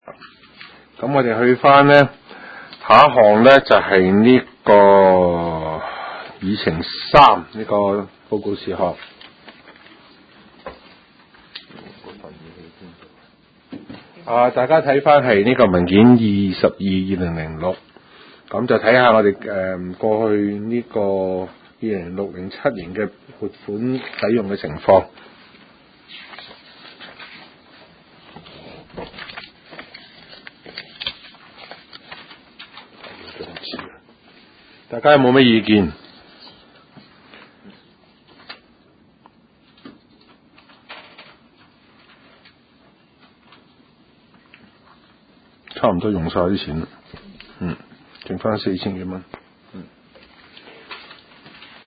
經濟及就業委員會第十八次會議
灣仔民政事務處區議會會議室